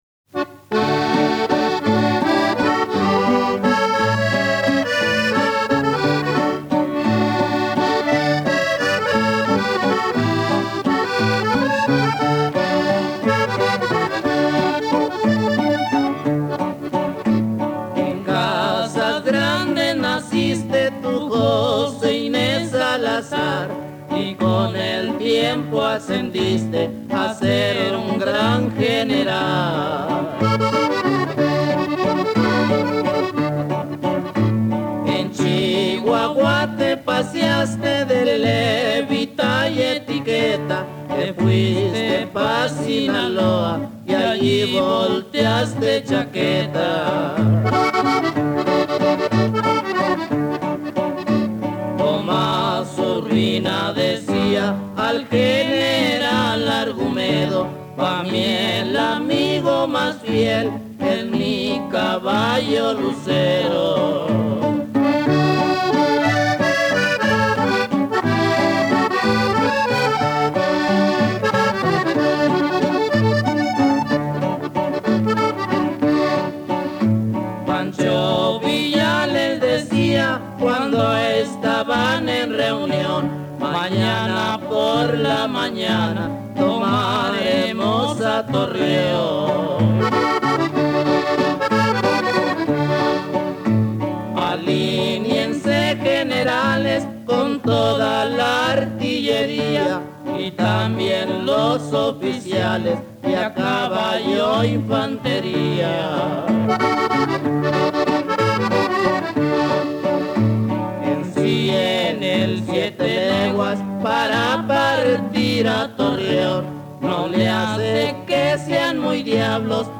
Corrido de la decena de Torreón (1914)